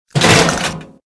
CHQ_GOON_hunker_down.ogg